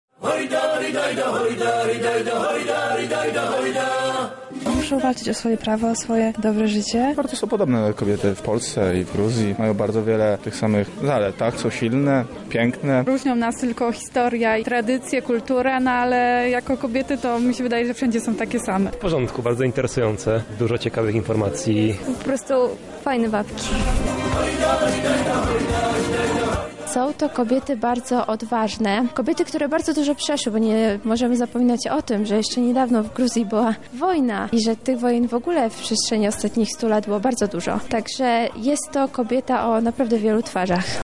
„To co zobaczyliśmy było niesamowite” – mówią uczestnicy projektu: